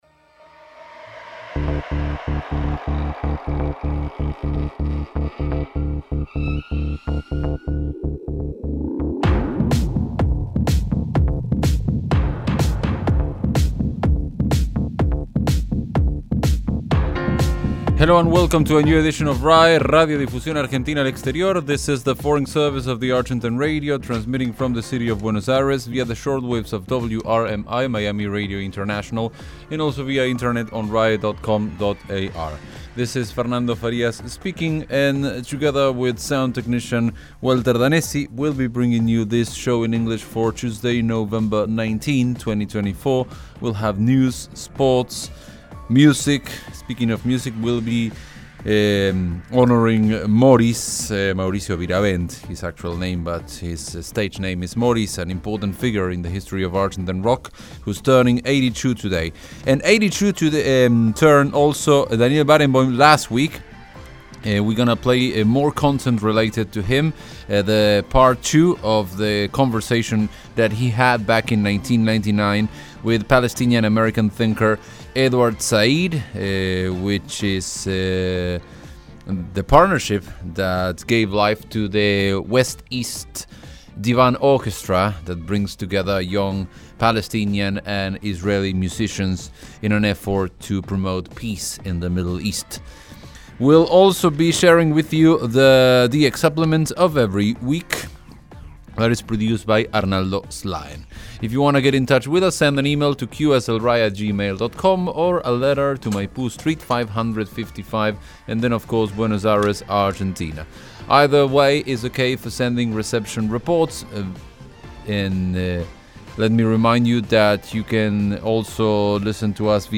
Part II of the conversation between maestro Barenboim and Edward Said recorded in 1999.